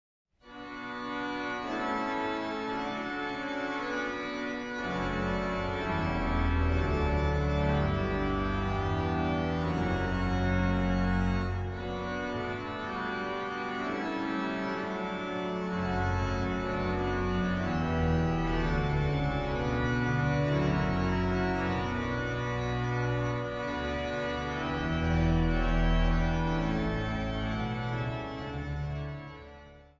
Niet ritmisch gezongen Psalmen met tegenstem
Zang | Gemengd koor
Zang | Kinderkoor